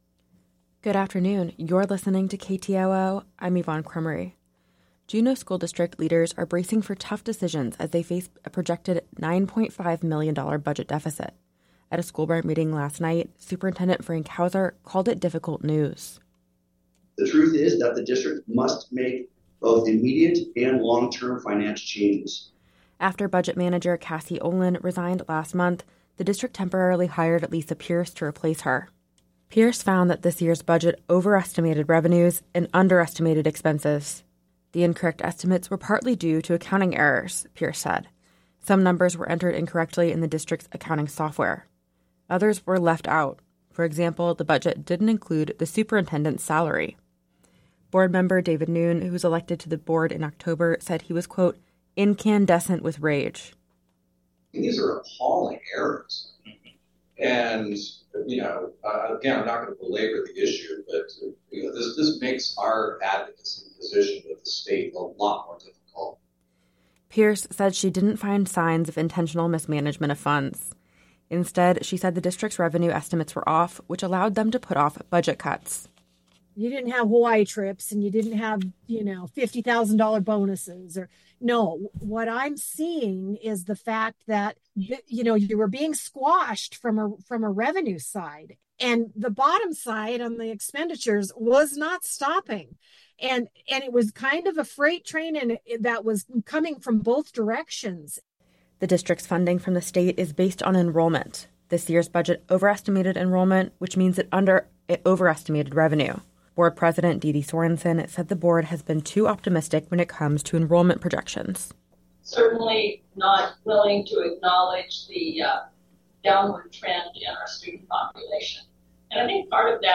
Newscast – Wednesday, Jan. 10, 2024